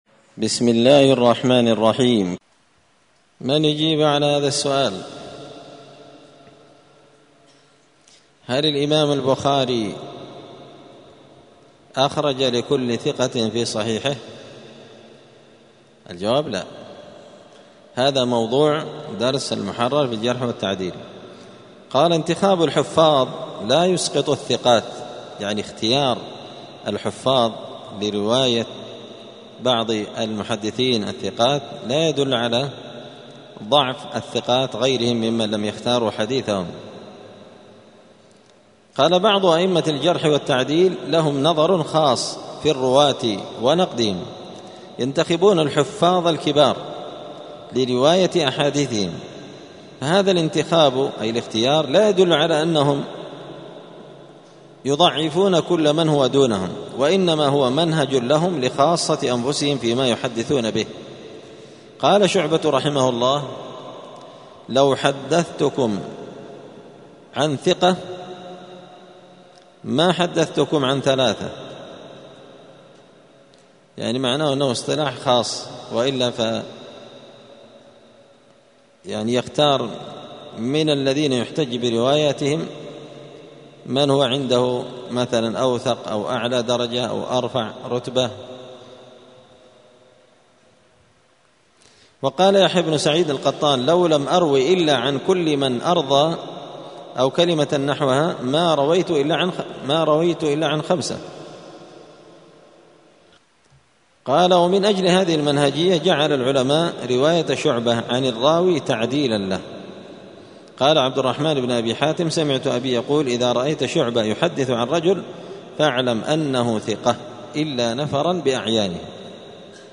دار الحديث السلفية بمسجد الفرقان بقشن المهرة اليمن
*الدرس الخمسون (50) باب انتخاب الحفاظ لا يسقط الثقات.*